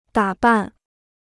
打扮 (dǎ ban) Free Chinese Dictionary